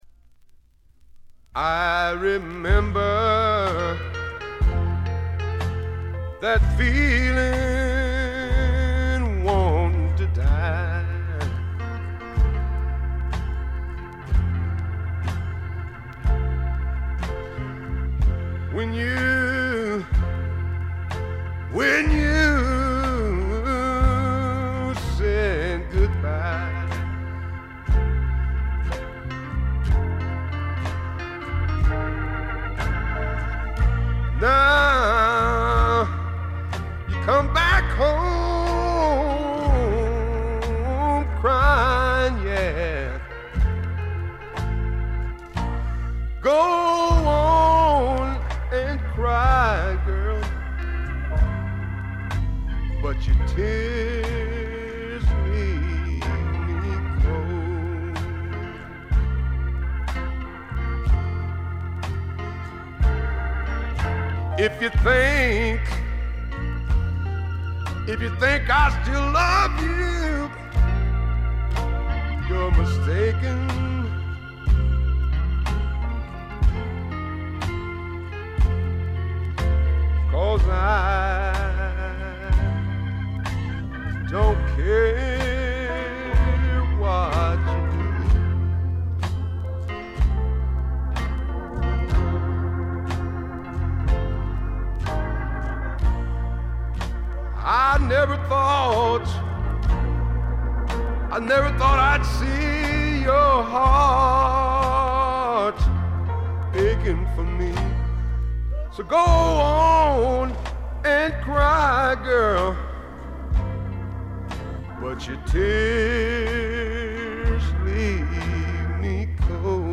テキサス産スワンプポップの名作。
試聴曲は現品からの取り込み音源です。